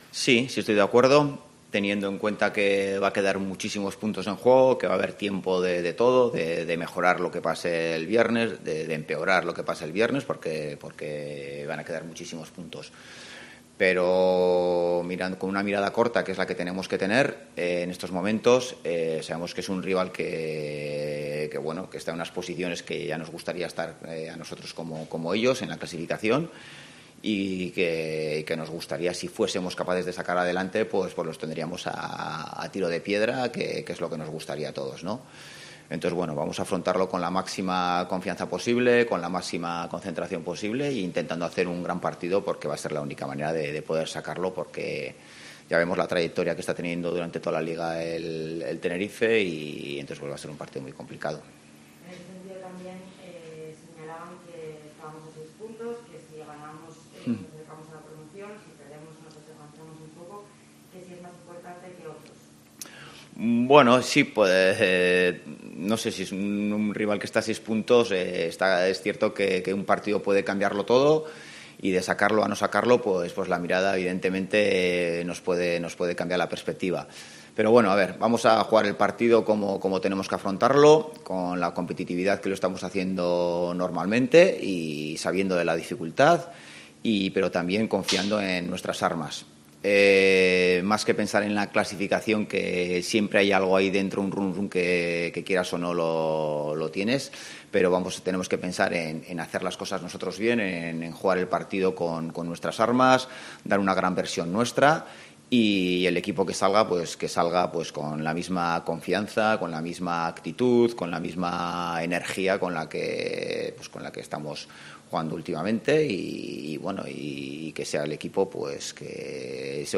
Rueda de prensa Ziganda (Tenerife-Oviedo)